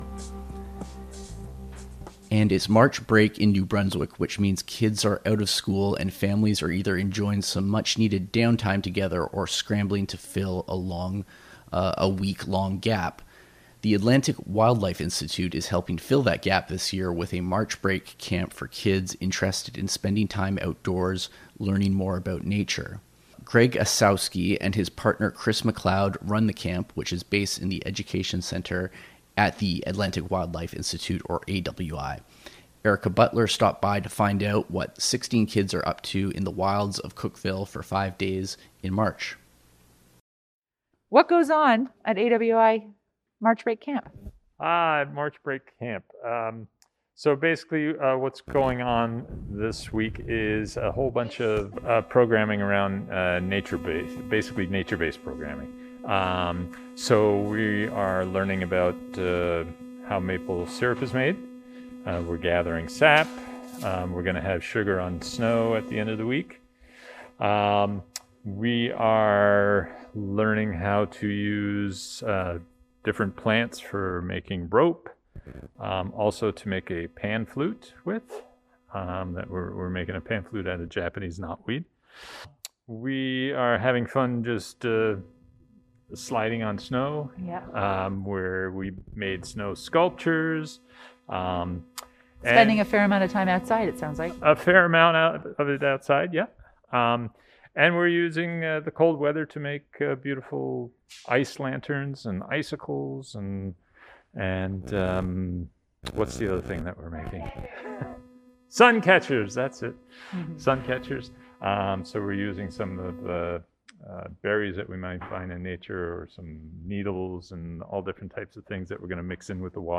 The Atlantic Wildlife Institute (AWI) in Cookville is helping fill that gap this year with a March break camp for kids interested in spending time outdoors, learning more about nature. CHMA dropped by the camp to hear from outdoor educator